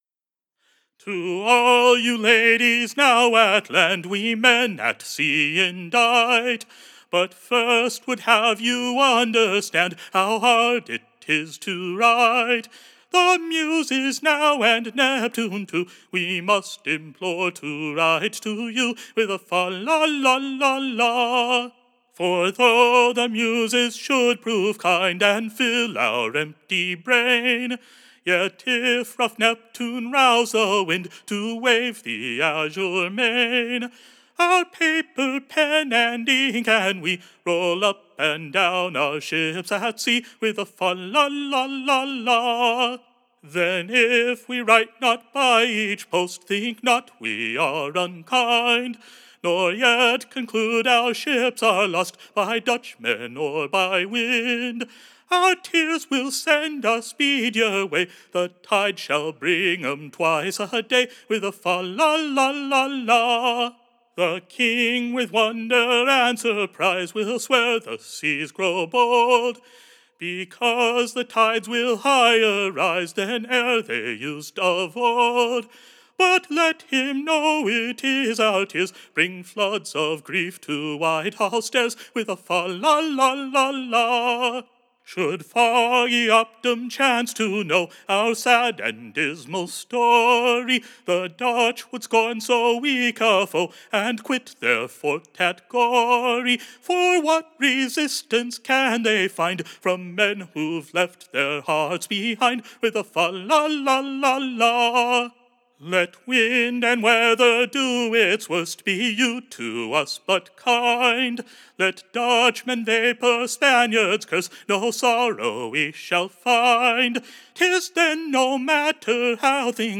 Ballad by Charles Sackville